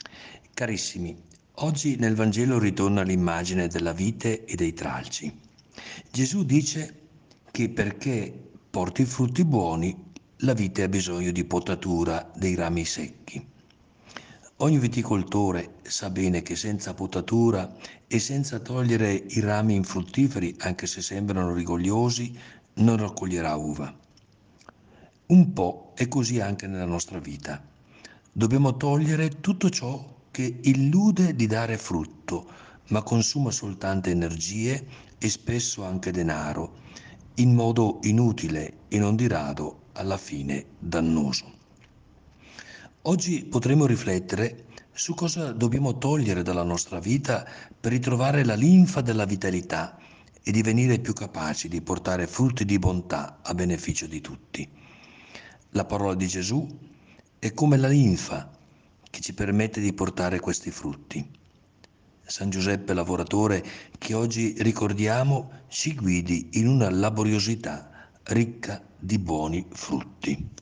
Il Vescovo commenta la Parola di Dio per trarne ispirazione per la giornata.